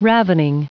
Prononciation du mot ravening en anglais (fichier audio)
Prononciation du mot : ravening